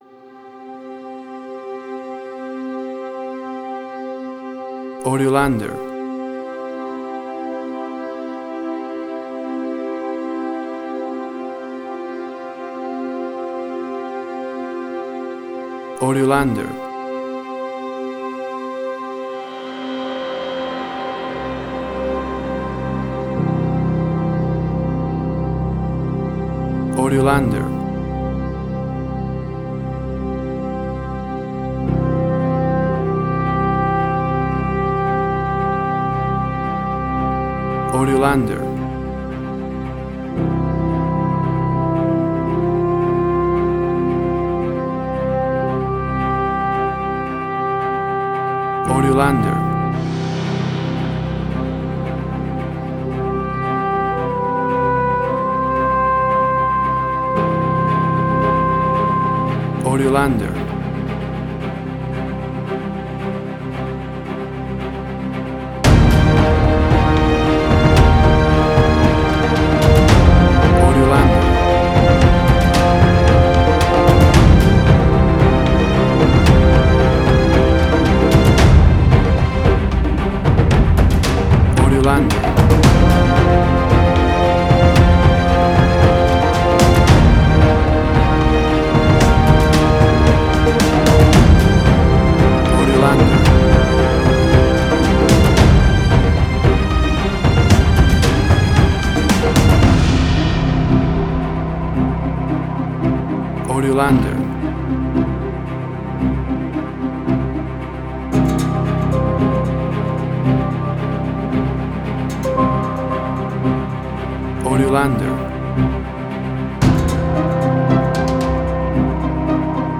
Suspense, Drama, Quirky, Emotional.
Tempo (BPM): 113